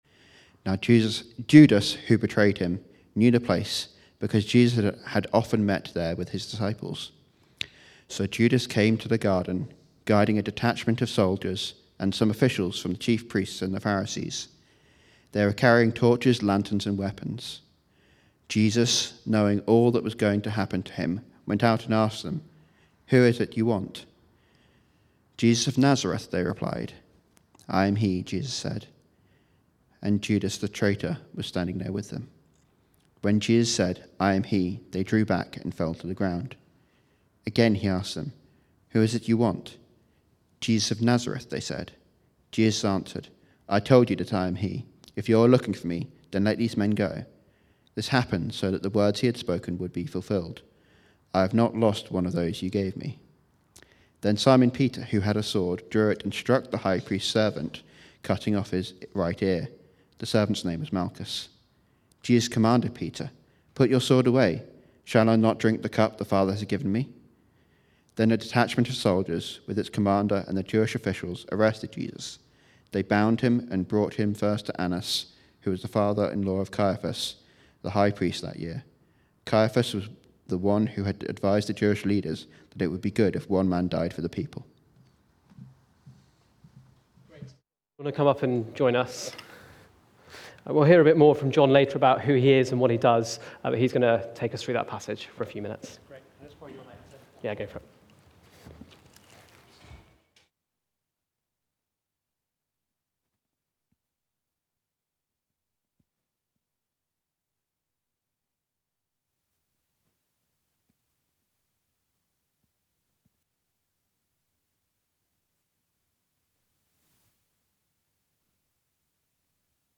Preaching
Recorded at Woodstock Road Baptist Church on 19 March 2023.